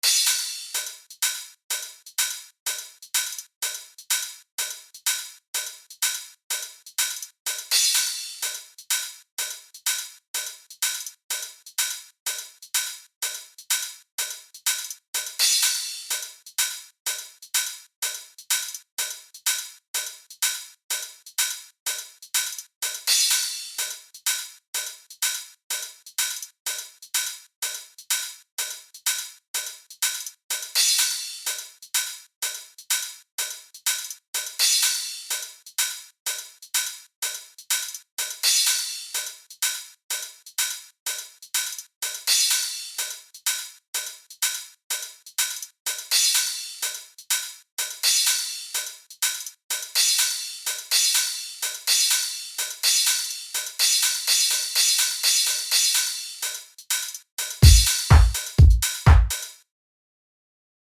Build 3/Breakdown:
However, these parts, without the bass drum, actually create the ideal rhythmical effect that I wanted for this part, as the lack of a bass drum gives this part a much more open feeling.
Here is a bounce of the drums depicted here, with no FX or automation: